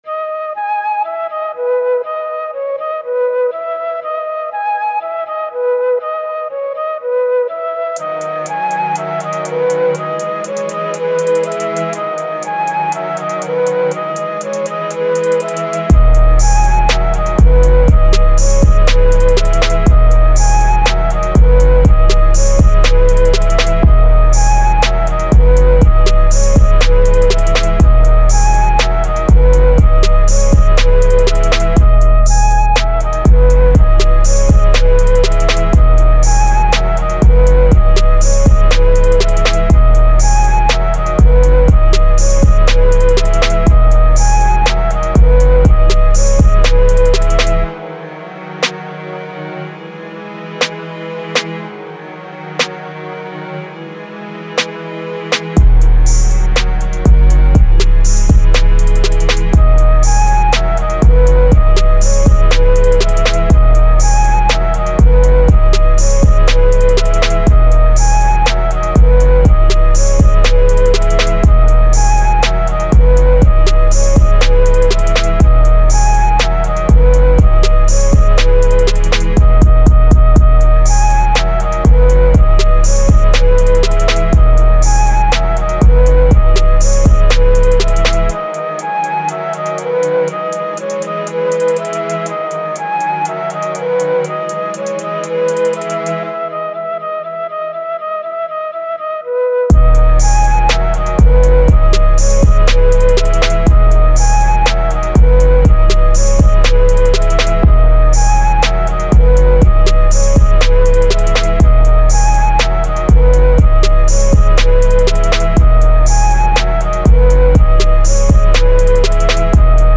Жанр: Rap, Trap, Drill, Hip-Hop, Rage, Soft Plug, Cloud Rap